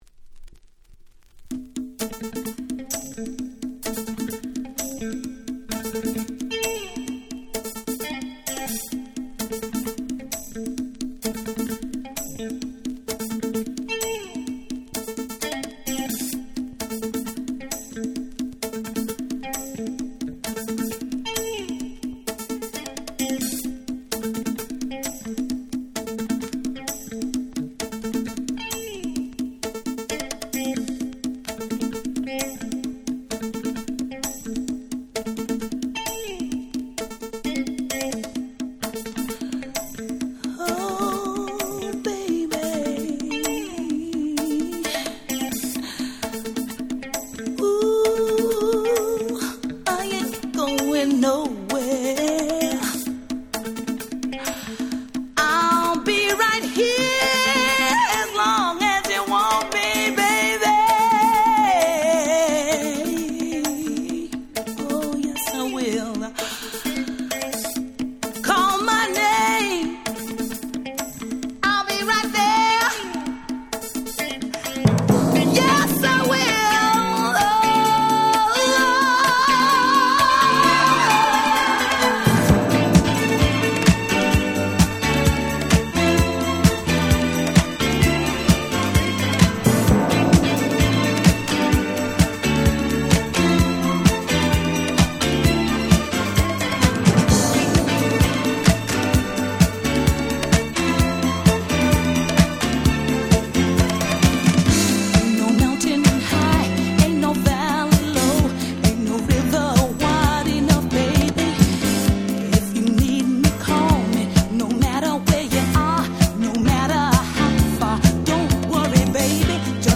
3曲共に言わずと知れた最高のDisco / Boogie !!